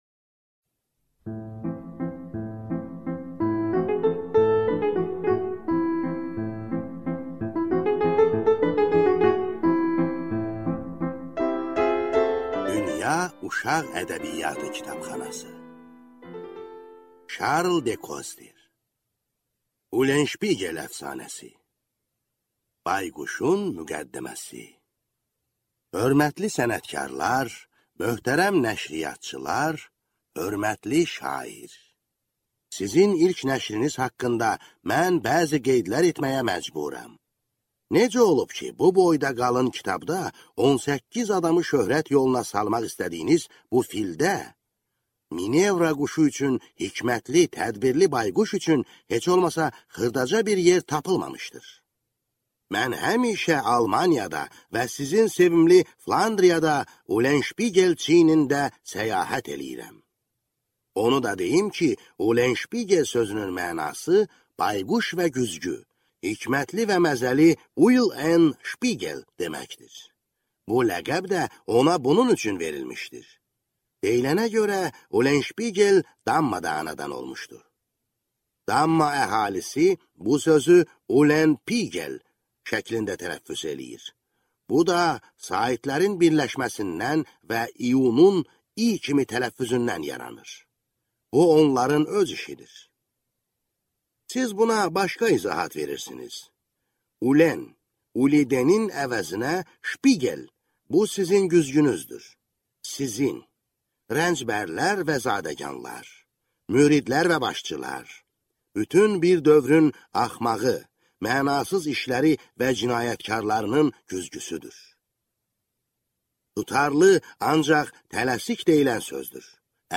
Аудиокнига Ulensşpigel əfsanəsi | Библиотека аудиокниг